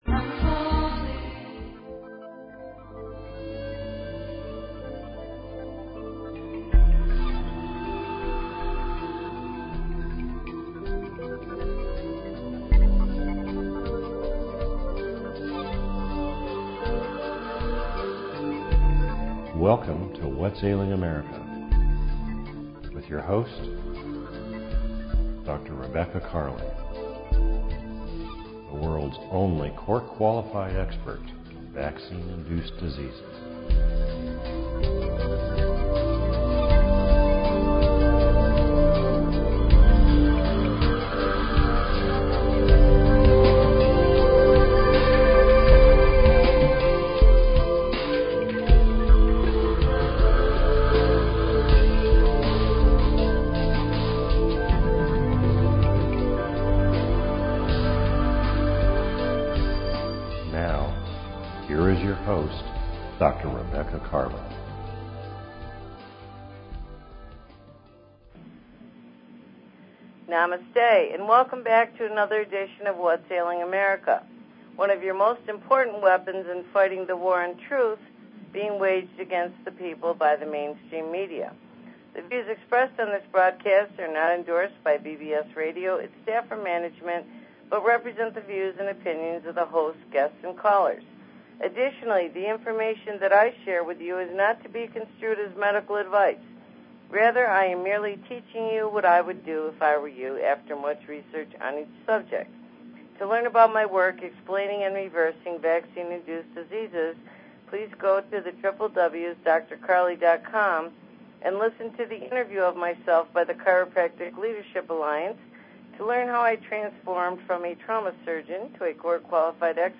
Talk Show Episode, Audio Podcast, Whats_Ailing_America and Courtesy of BBS Radio on , show guests , about , categorized as